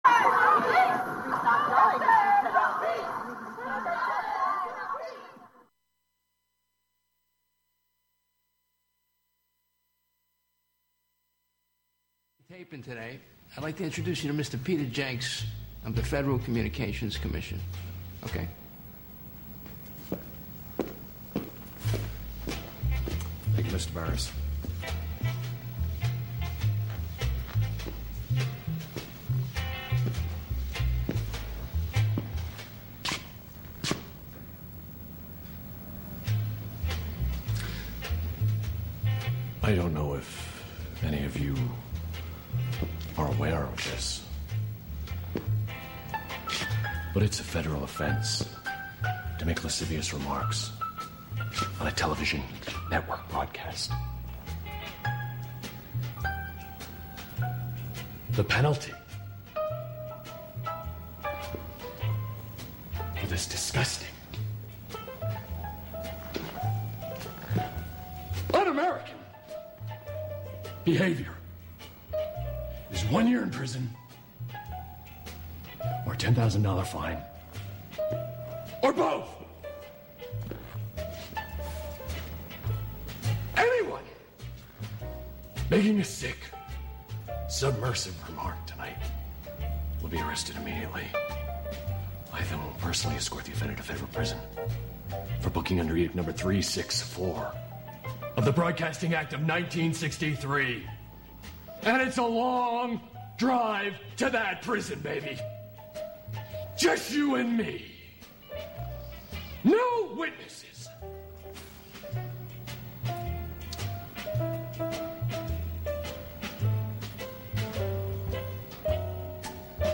listen to and discuss each other's favorite songs and singers, take requests from callers, give advice, and even cast a spell or two